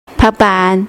wnmj-normal/Resources/Games/WNMJ/WanNianMJ/Woman/37.mp3 at main